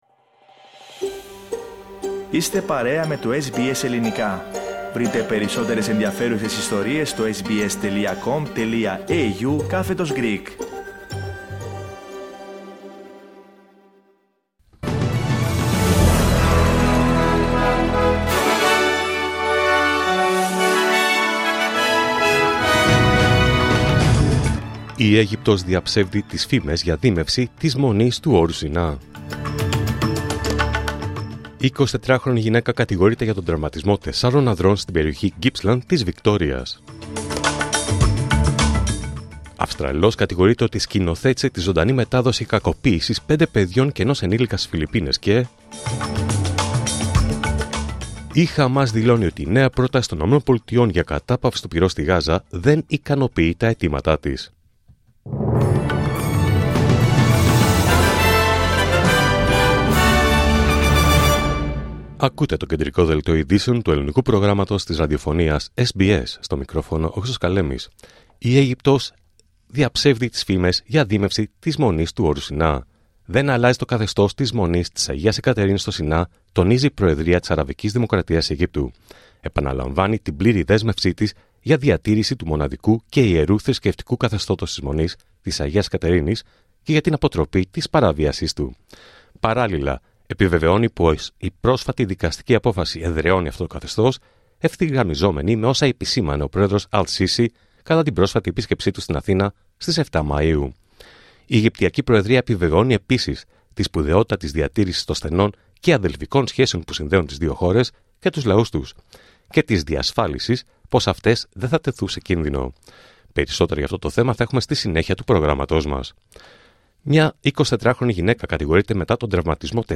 Δελτίο Ειδήσεων Παρασκευή 30 Μαΐου 2025